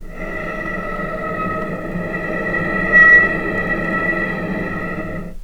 vc_sp-E5-pp.AIF